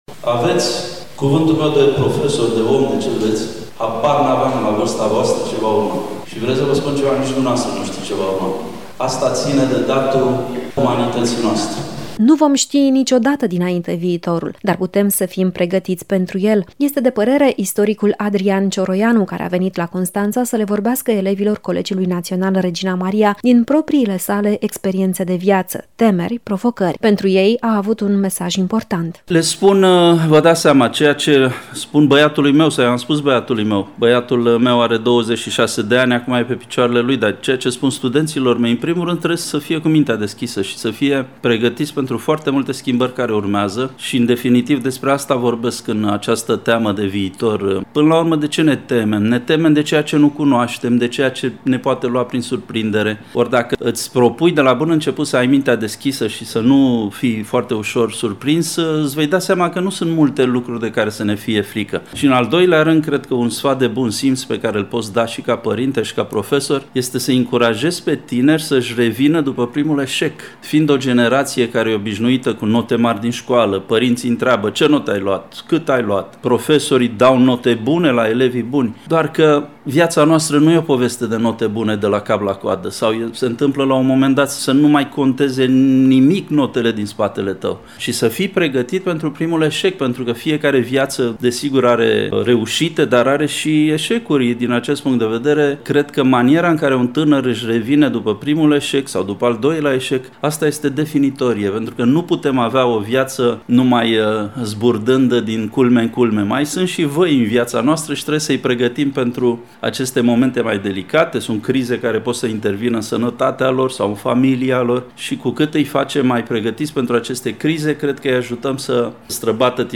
În sala de spectacole „Sergiu Celibidache”, istoricul Adrian Cioroianu a vorbit în fața a zeci de liceeni de la Colegiul Național “Regina Maria” din Constanța. Și nu cu tonul oficial al profesorului sau al omului de știință, ci cu cel al părintelui.
reportaj